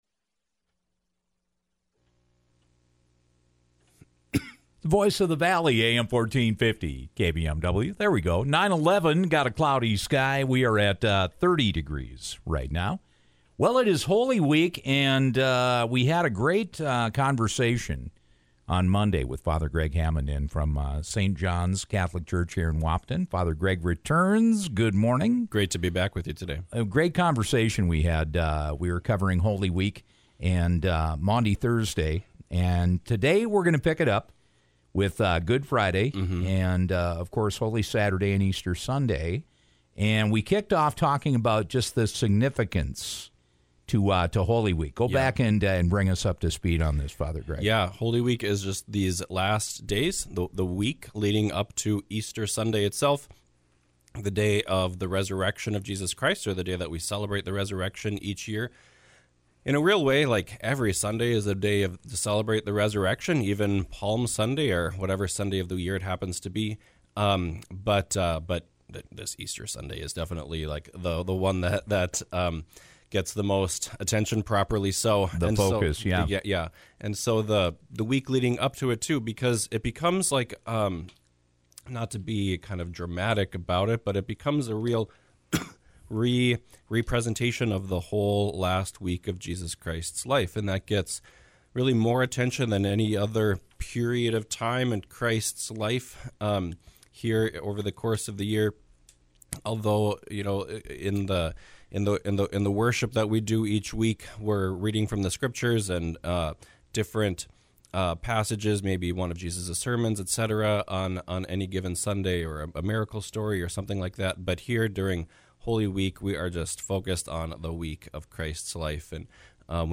We picked up our conversation